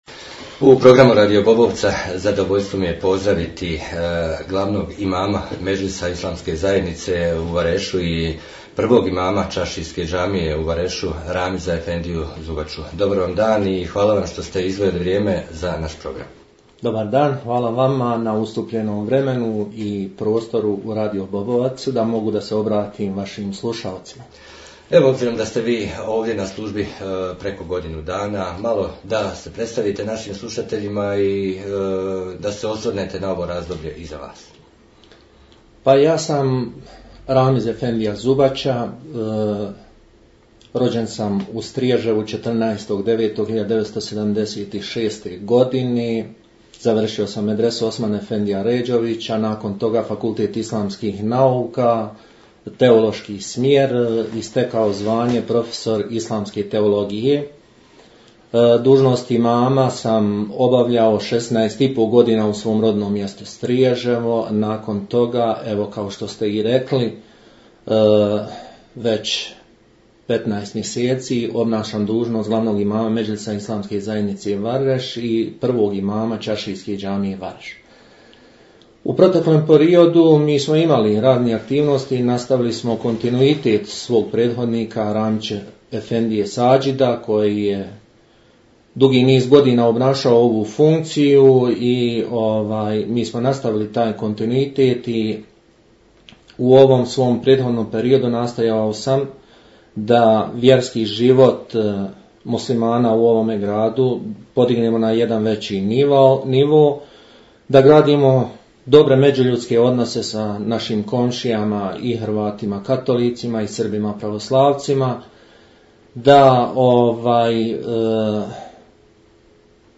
razgovor o Ramazanu